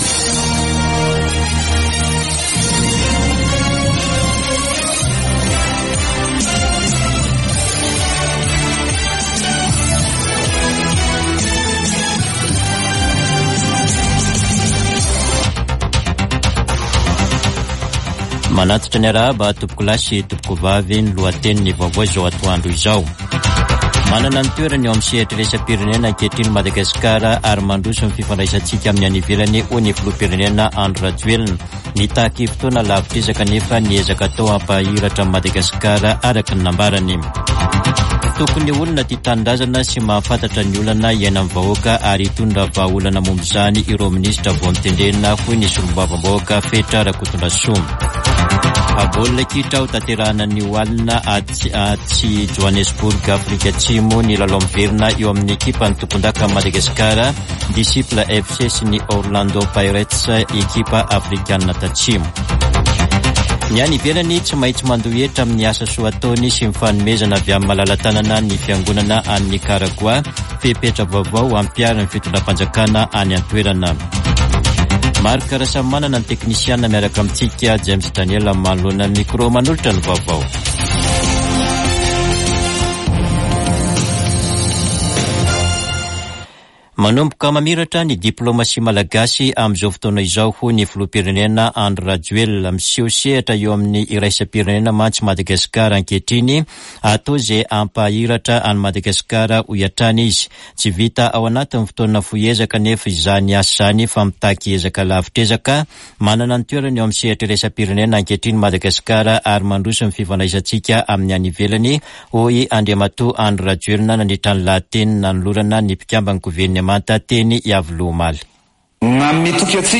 [Vaovao antoandro] Zoma 23 aogositra 2024